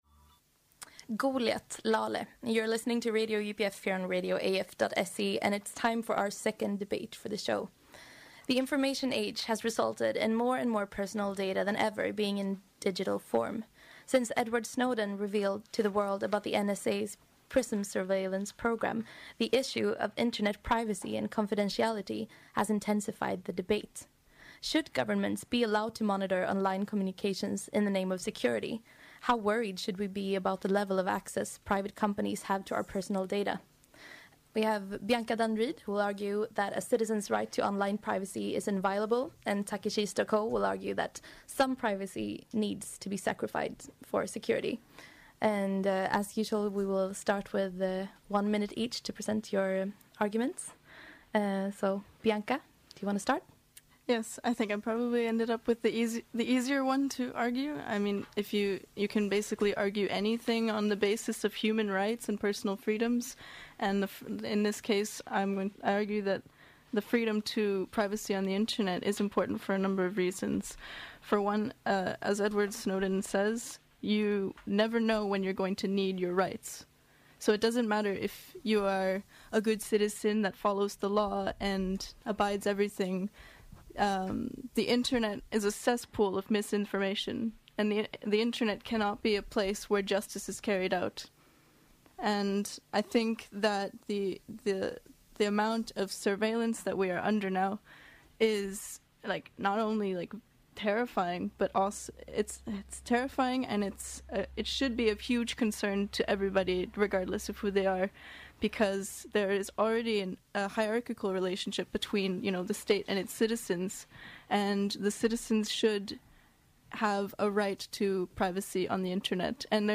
Debate: Internet & Privacy